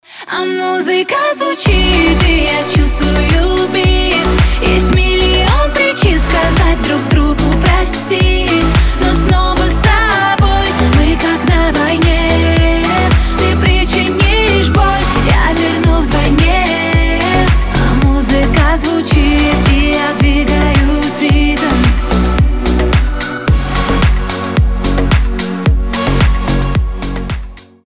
• Качество: 320, Stereo
поп
женский вокал